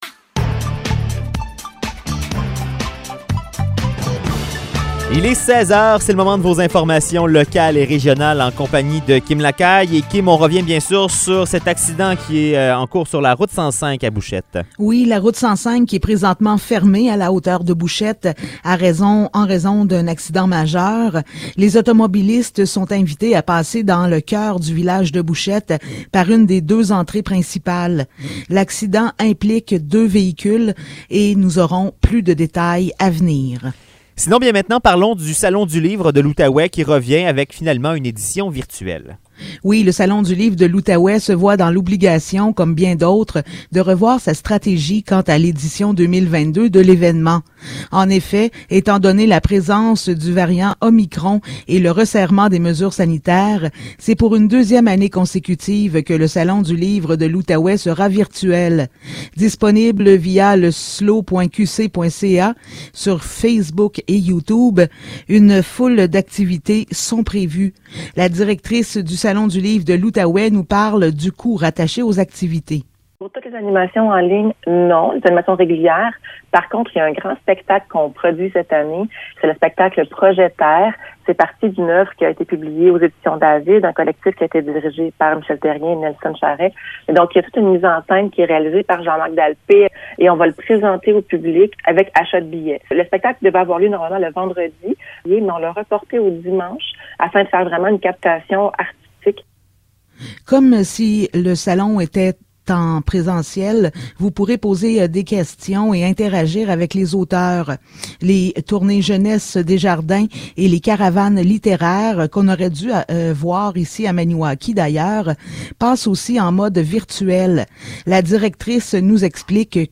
Nouvelles locales - 18 janvier 2022 - 16 h